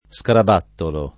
scarabattolo [ S karab # ttolo ] s. m.